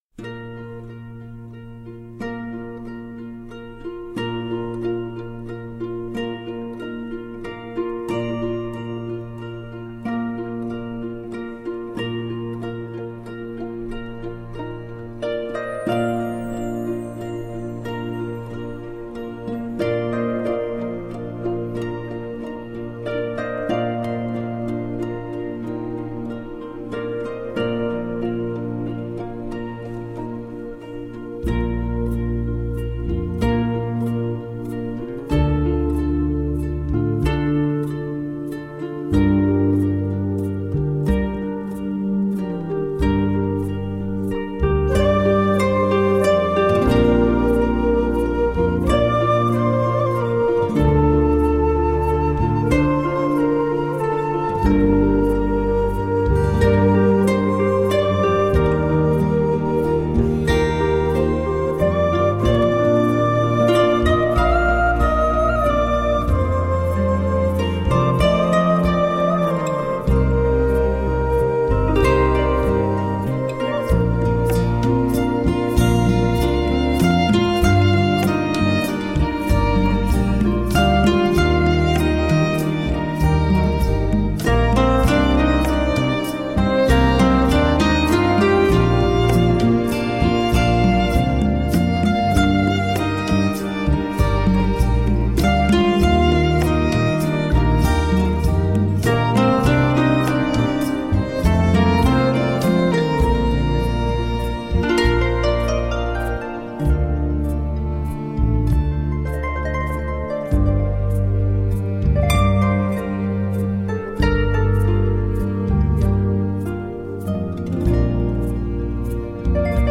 Tagged as: World, Folk, New Age, Celtic, Harp